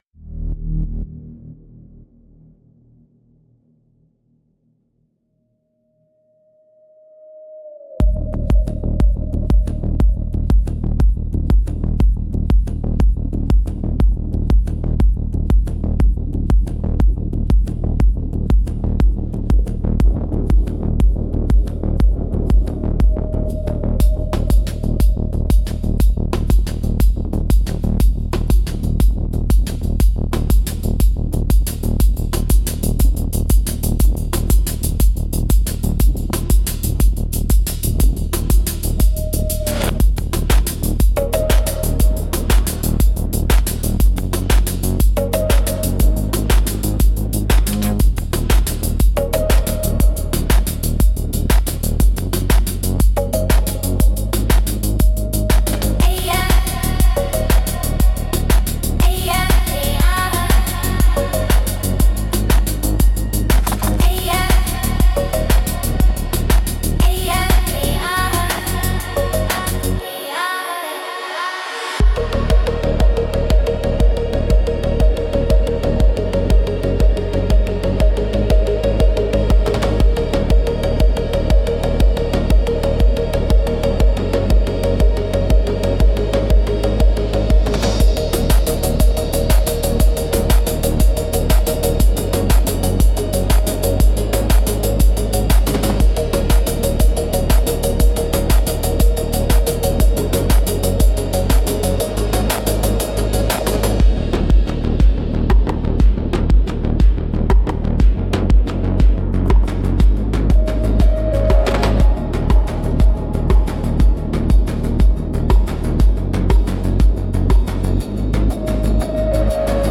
Instrumentals - Echoes from the Data Fire